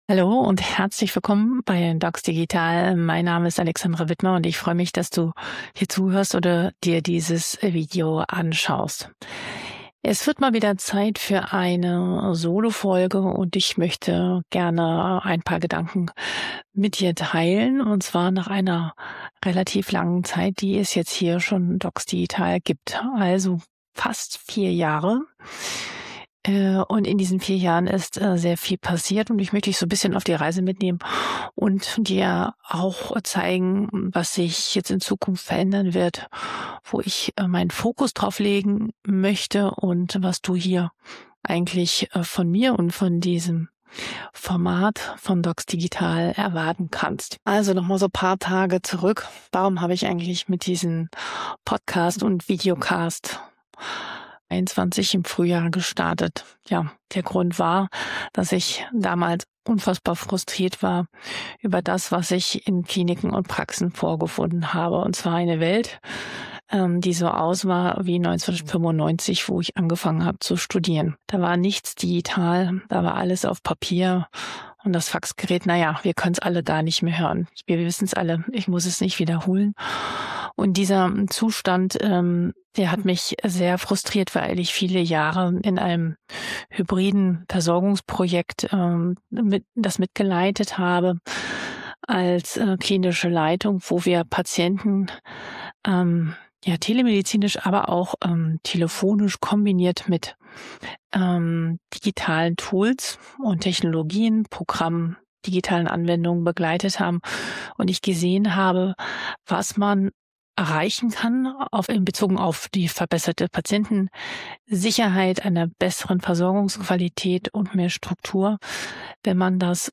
In dieser Solofolge teile ich meine Gedanken zur Weiterentwicklung von docsdigital. Ich spreche darüber, was sich in der Versorgung gerade verändert, warum KI nicht mehr wegzudenken ist und welche Fragen mich aktuell wirklich beschäftigen.